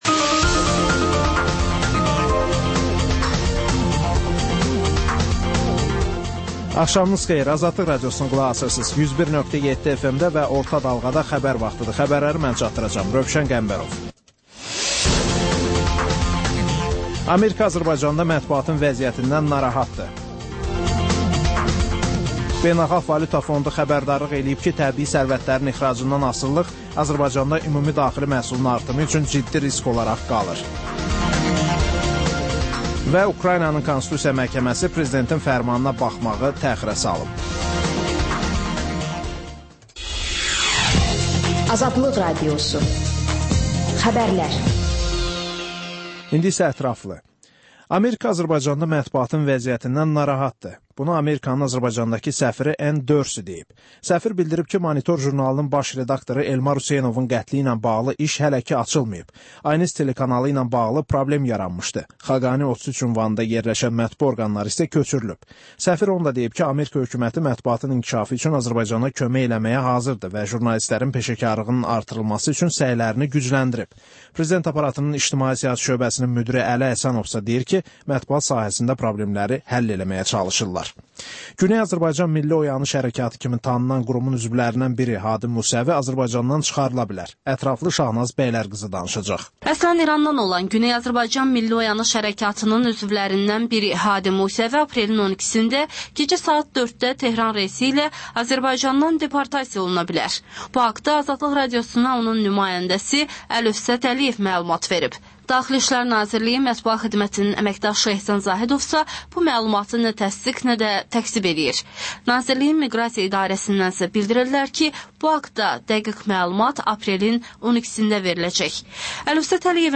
Xəbərlər, müsahibələr, hadisələrin müzakirəsi, təhlillər, sonda 14-24: Gənclər üçün xüsusi veriliş